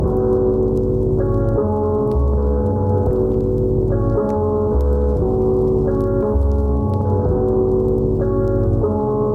描述：破损的乙烯基
Tag: 77 bpm Hip Hop Loops Piano Loops 1.57 MB wav Key : Unknown